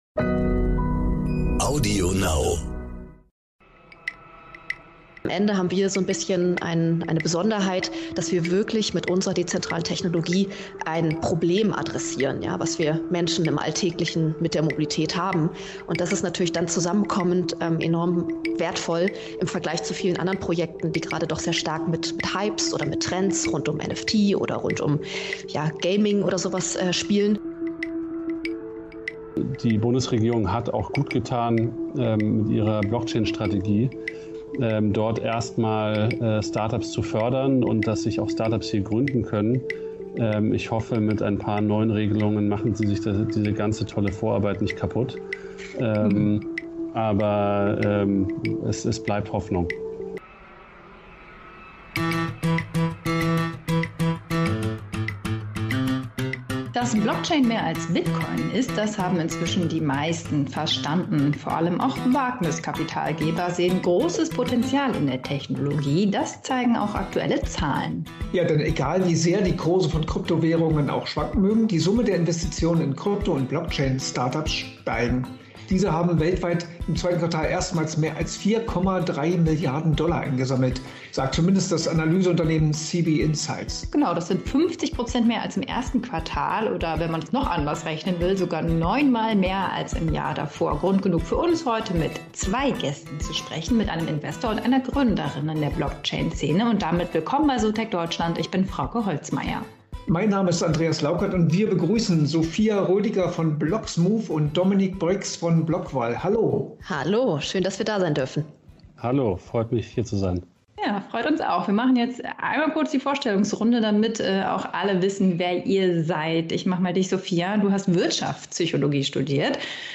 Beide sind sich einig: Deutschland ist grundsätzlich ein gutes Startup-Land für Blockchain-Unternehmen. Aber Luft nach oben gibt es noch genug, wie die beiden "So techt Deutschland" im Doppelinterview erzählen. Das ehrgeizige Ziel von Bloxmove: Mobilitätsanbieter auf einer Plattform vereinen, damit Verbraucher nicht mehrere Apps benutzen müssen, um in Deutschland von A nach B zu kommen.